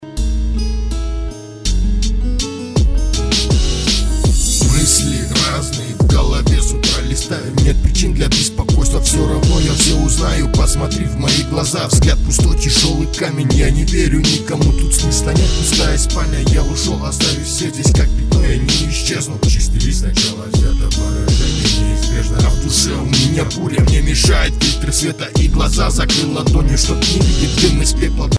• Качество: 128, Stereo
гитара
лирика
русский рэп